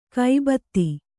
♪ kai batti